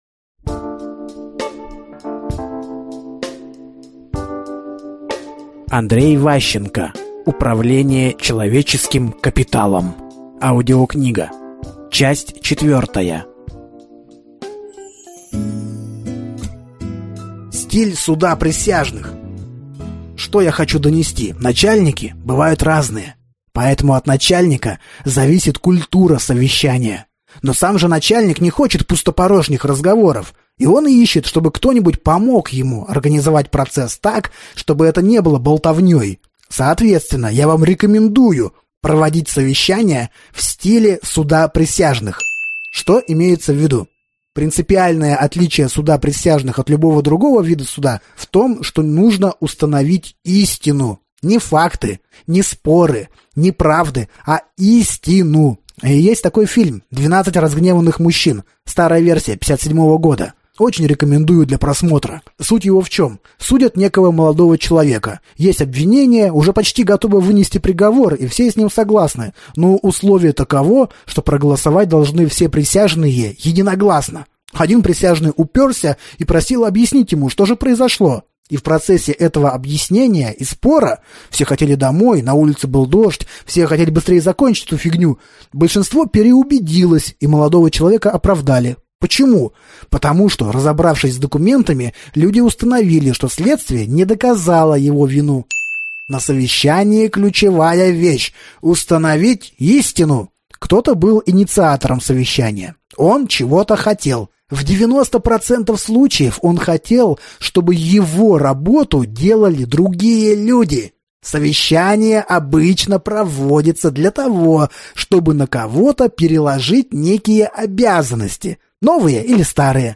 Аудиокнига Управление человеческим капиталом. Часть 4 | Библиотека аудиокниг